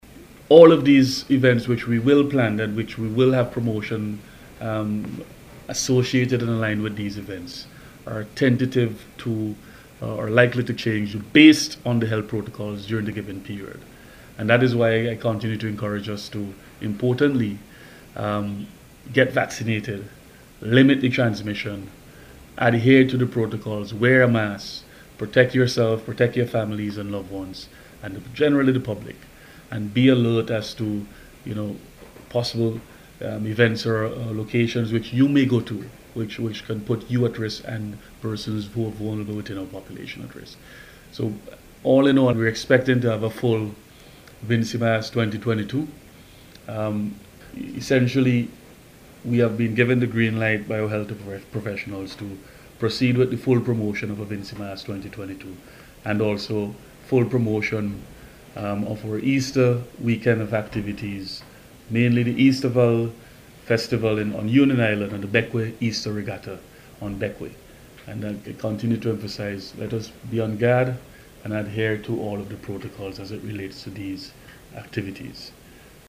Minister of Culture, Carlos James announced at a Media Conference this morning that the Ministry of Health has given the green light for the festival.